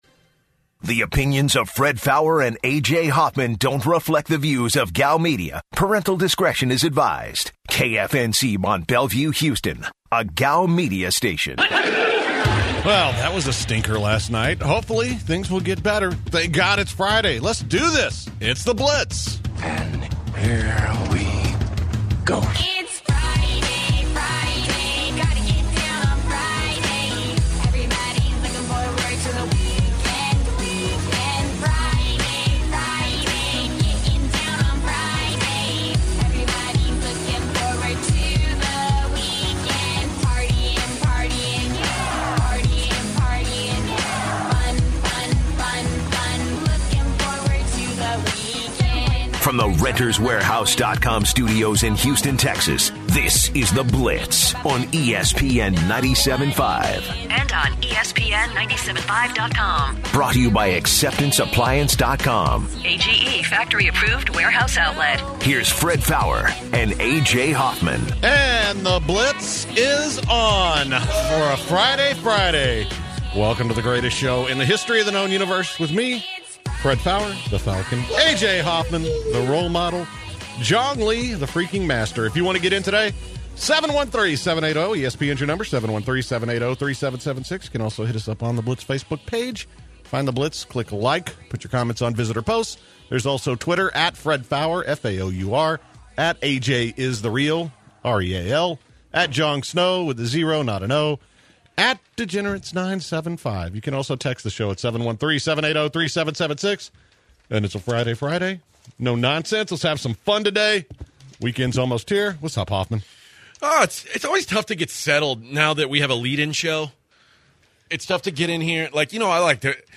The guys started talking about food problems at the office. They moved into the Thursday Night game. The last 2 segments included phone calls from the listeners.